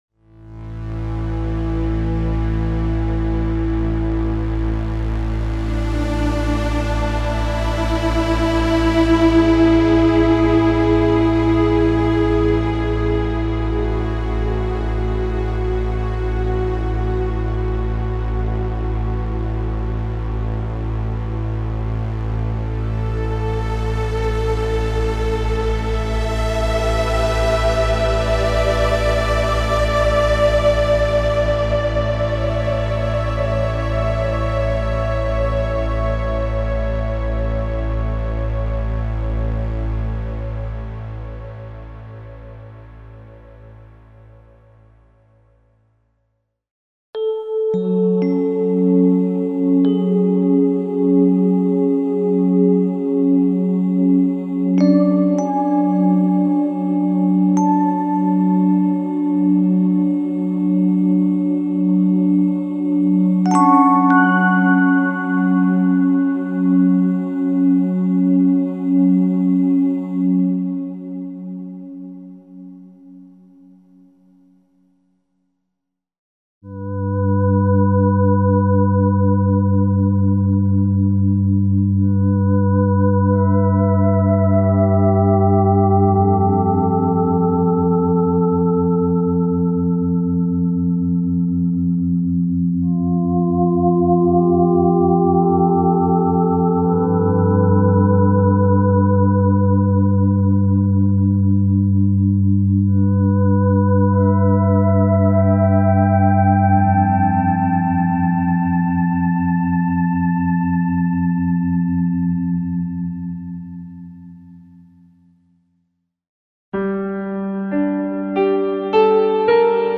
Ambient - special sound programs for ambient and new age music (warm and "ice-cold" synth pads, synth leads, strings, voices, electric & acoustic pianos and piano combinations).
Info: All original K:Works sound programs use internal Kurzweil K2500 ROM samples exclusively, there are no external samples used.